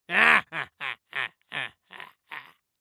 Laugh_old_man_6
cackle evil laugh laughter man old sound effect free sound royalty free Funny